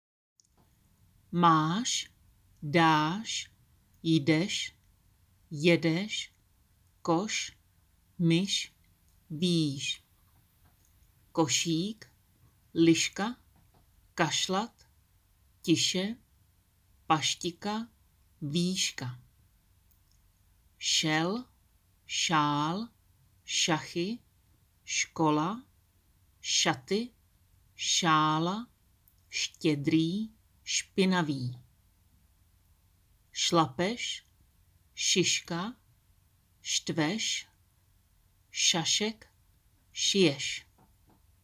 Tady si můžete stáhnout audio na výslovnost Š – slova.
š_slova.m4a